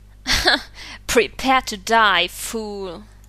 Englische Sprecher (f)
Selbsteinsch�tzung / Self-characterisation: arrogant